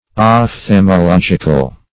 Search Result for " ophthalmological" : The Collaborative International Dictionary of English v.0.48: Ophthalmological \Oph*thal`mo*log"ic*al\, a. Of or pertaining to ophthalmology.
ophthalmological.mp3